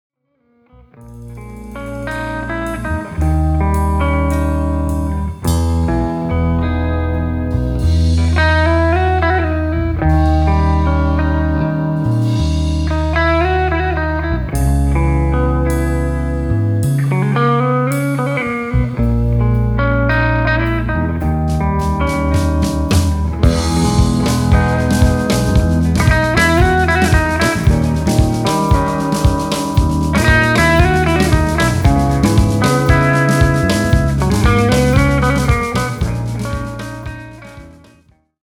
Recorded at Tony’s Treasures Studio, Cadiz, Ohio.